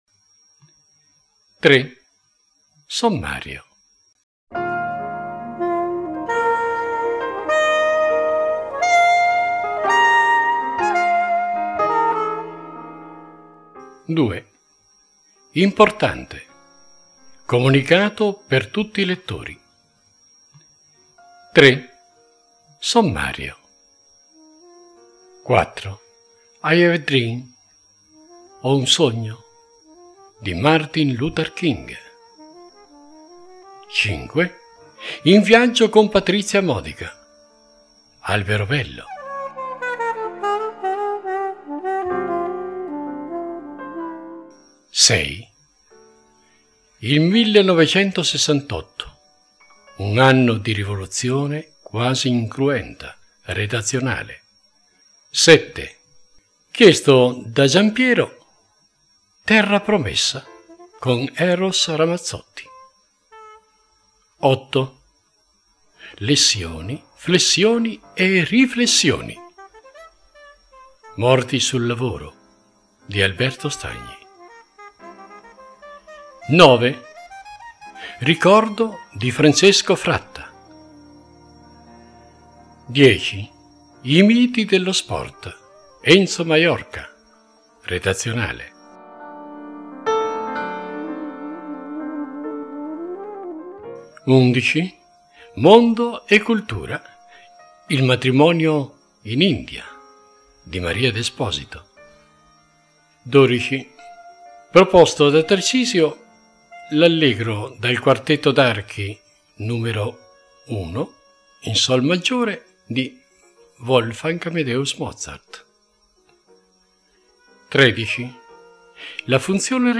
Il tutto intervallato da tanta musica di diversi generi, richiesta dai lettori.